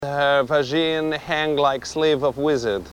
Tags: grindcore comedy horror gore insult